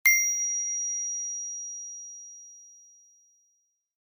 receive_message_sound.mp3